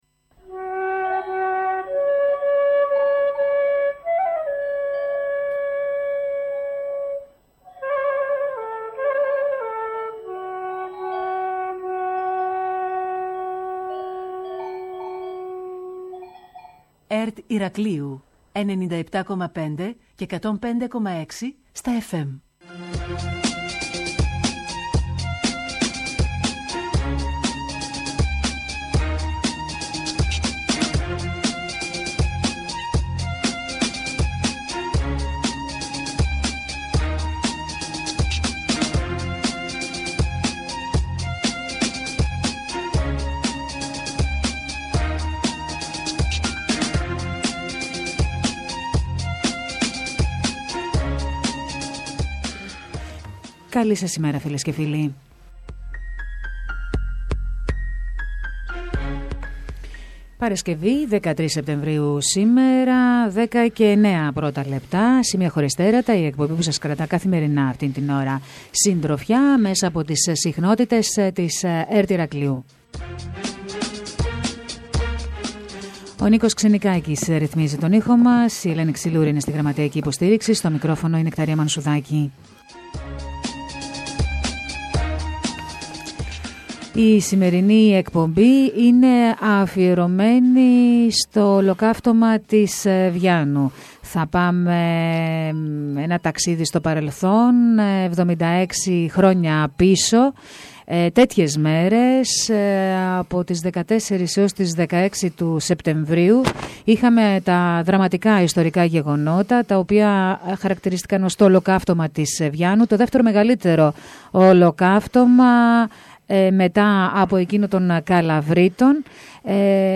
Το ολοκαύτωμα της Βιάννου μέσα από μαρτυρίες – αφιέρωμα της ΕΡΤ Ηρακλείου (ηχητικό)